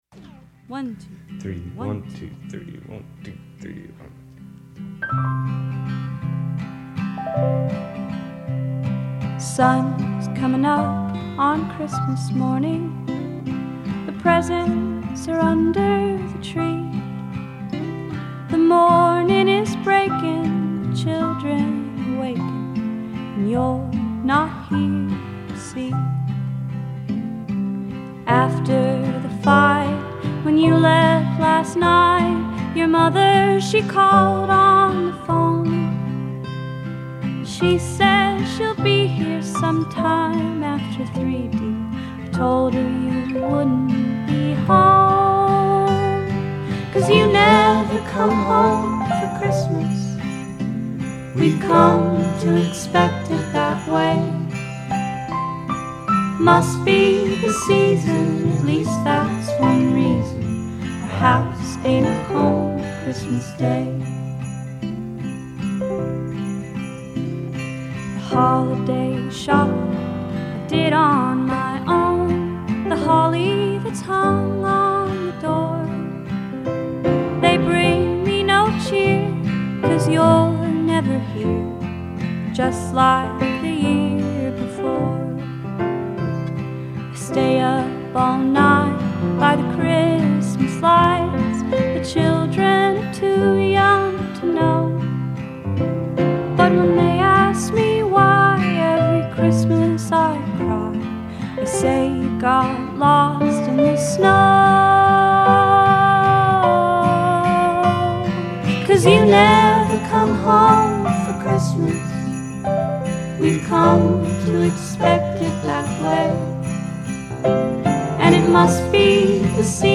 depressing Christmas song
countrified
With a light guitar strum and Zooey-meets-Patsy vocals